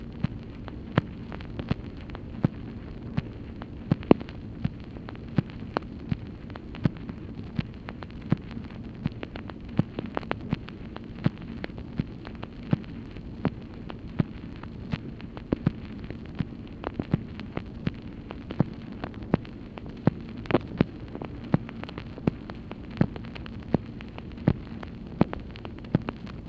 MACHINE NOISE, March 7, 1964
Secret White House Tapes | Lyndon B. Johnson Presidency